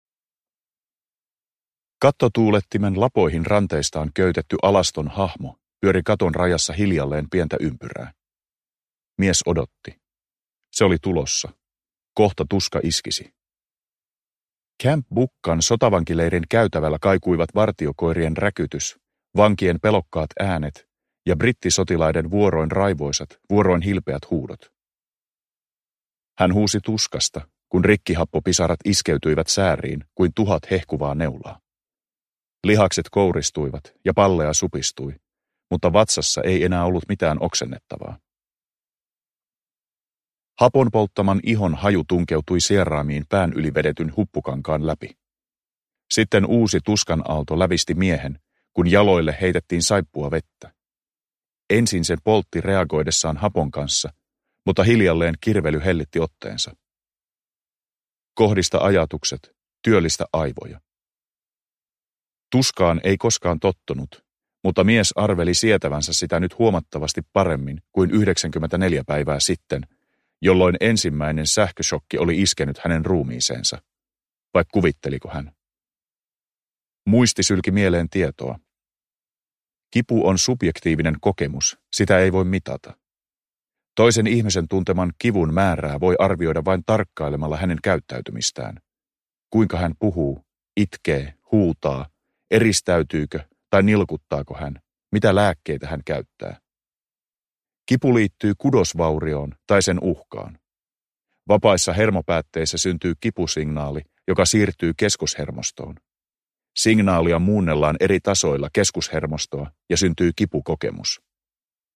Pimeyden ydin – Ljudbok – Laddas ner
Uppläsare: Jukka Peltola